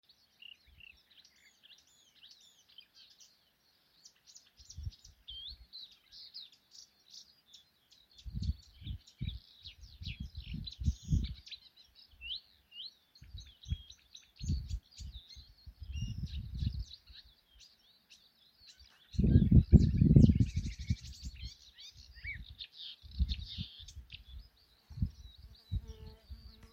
болотная камышевка, Acrocephalus palustris
Administratīvā teritorijaLīvānu novads
СтатусПоёт